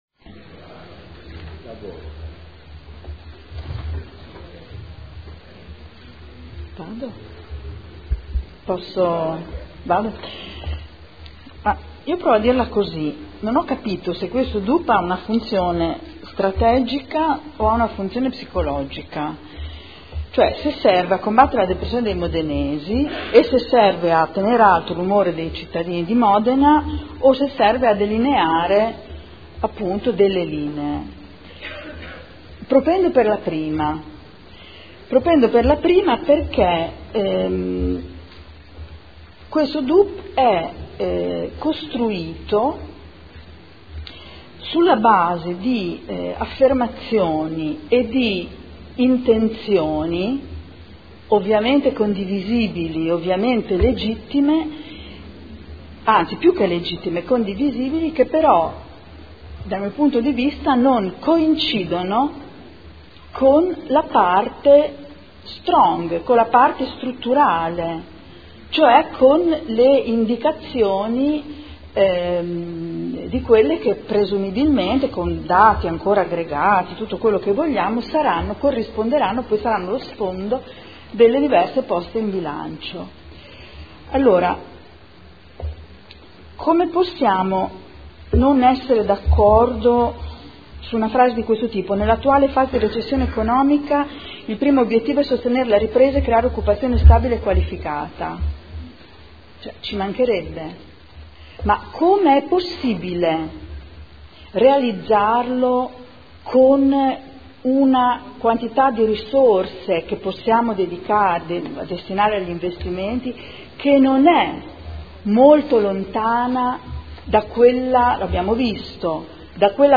Seduta del 29/01/2015.
Dichiarazione di voto